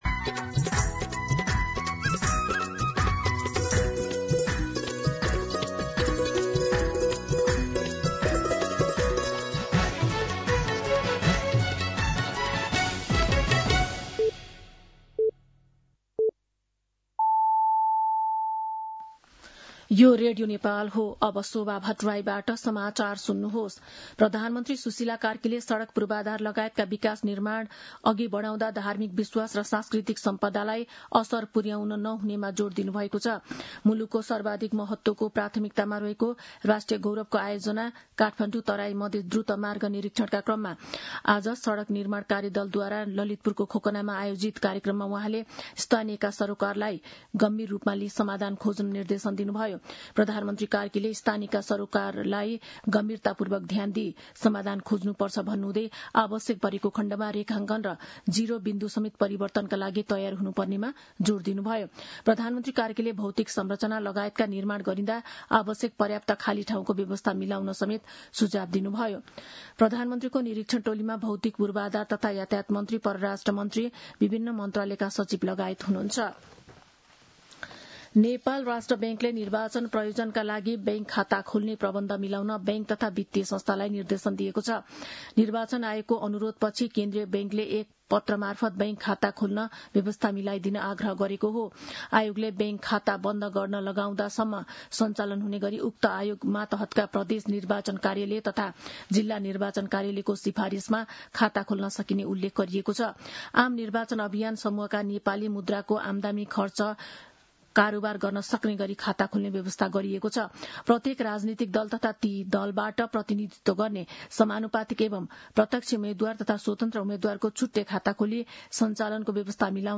मध्यान्ह १२ बजेको नेपाली समाचार : १३ माघ , २०८२